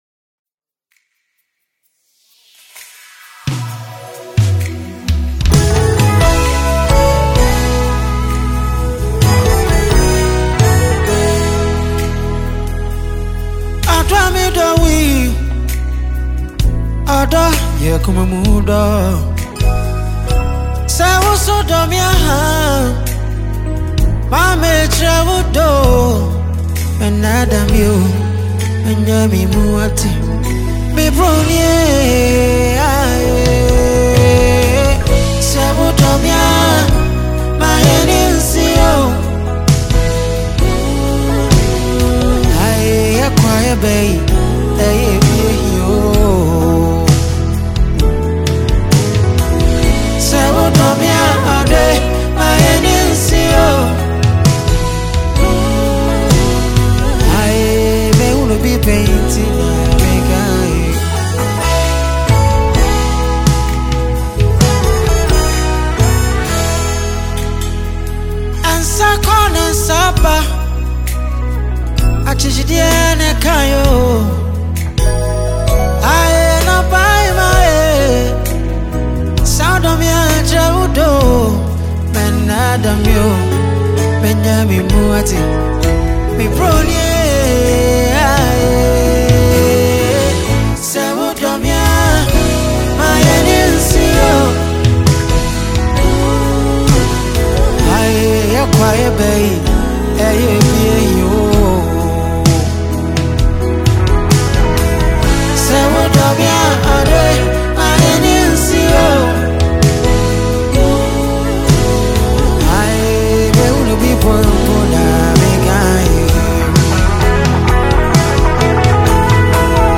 a Ghanaian highlife singer, producer, and songwriter
and this is a live performance.